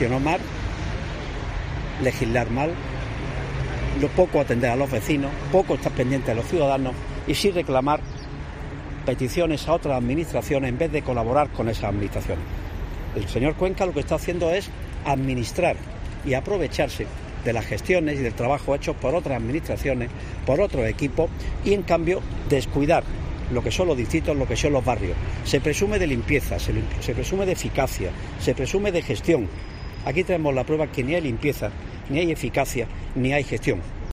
La concejal del Partido Popular en el Ayuntamiento Pepa Rubia indica que tenemos quejas constantes de los vecinos de todos los distritos